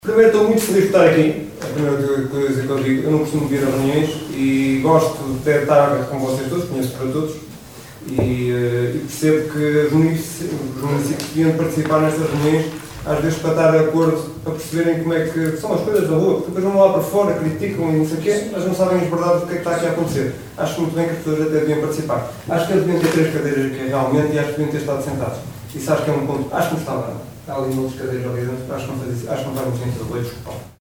Excertos da última reunião do executivo caminhense, no passado dia 6 de Novembro no Salão Nobre do edíficio dos Paços do Concelho.